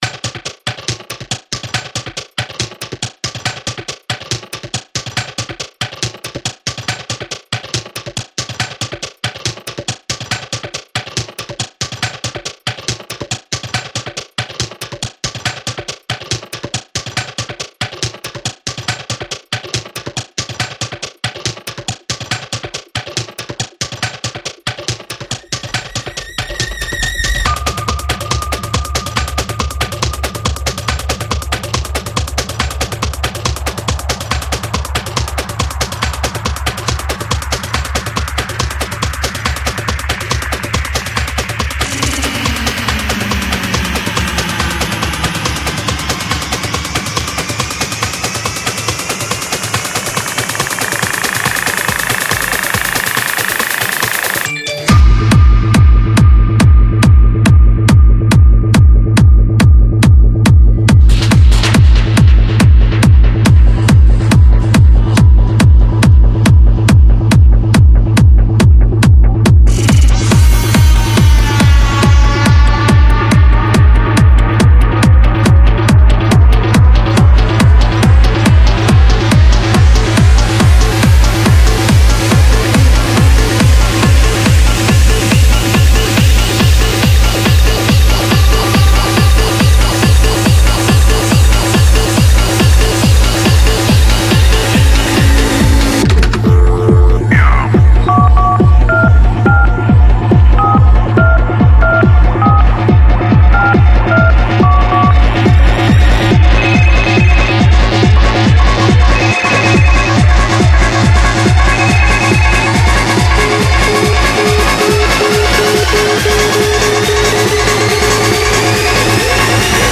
Стиль: Tech Trance